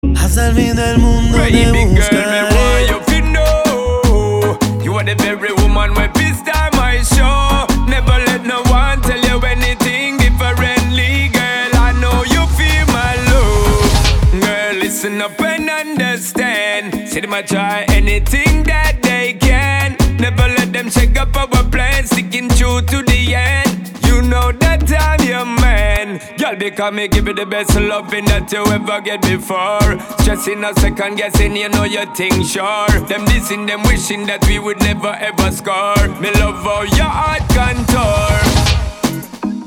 • Качество: 320, Stereo
мужской вокал
красивые
dance
вокал